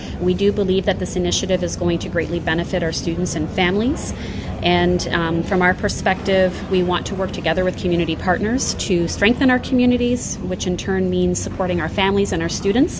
At a press conference held at Algonquin College